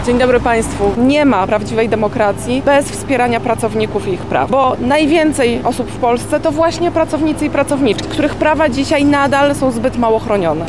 16 lipca o godzinie 11.00 przy pomniku Lubelskiego Lipca, odbyły się obchody upamiętniające strajki polskich robotników, które miały miejsce w 1980 roku.
– mówi Magdalena Bajat, Wicemarszałkini Senatu.